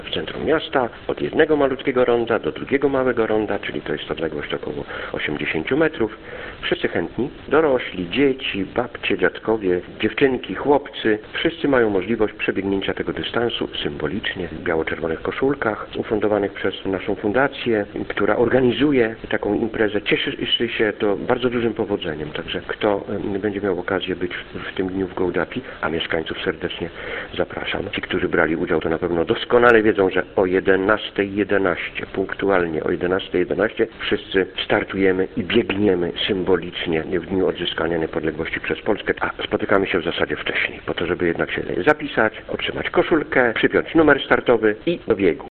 Mówi Jacek Morzy, zastępca burmistrza Gołdapi: